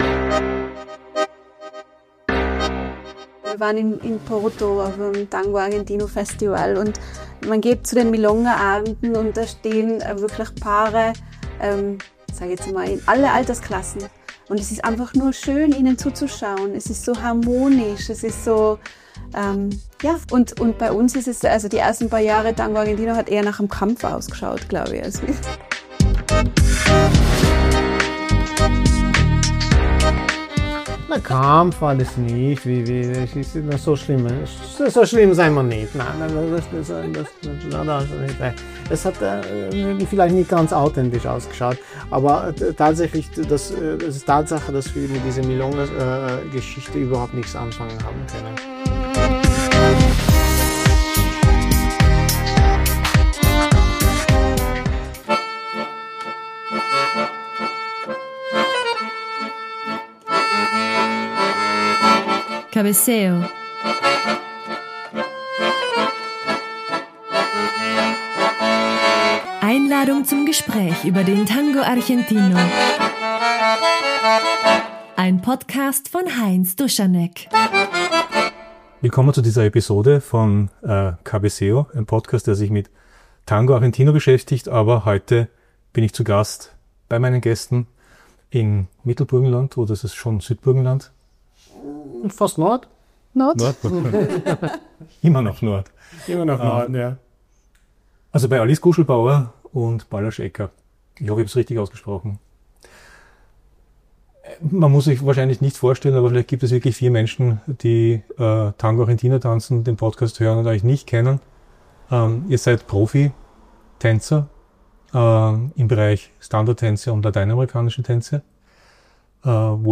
Die beiden Profitänzer im Gespräch über den Ballroom-Tango und den Tango Argentino.